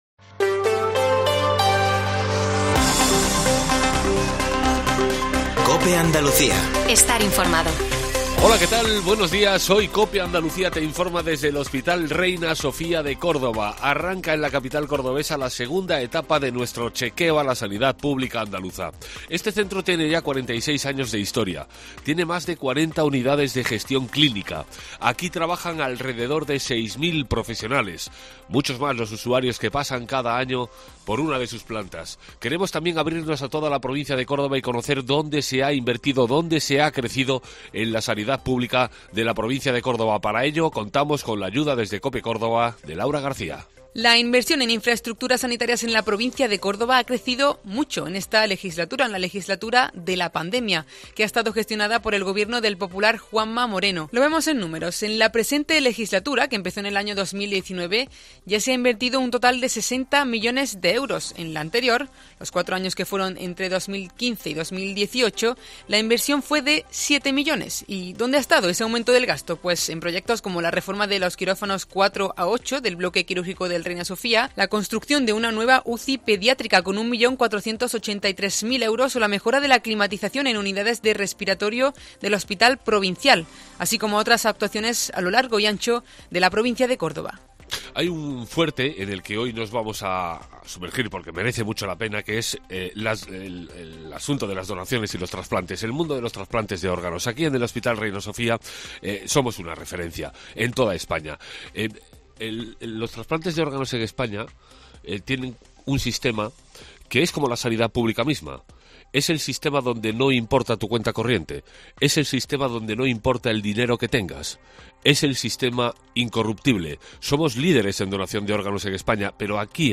Herrera en COPE Andalucía 07.50 – 31 de marzo. Desde el Hospital Universitario Reina Sofía de Córdoba